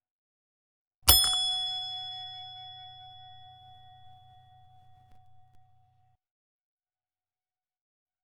hotel-bell-1
bell chrome ding foley hotel metal ping reception sound effect free sound royalty free Sound Effects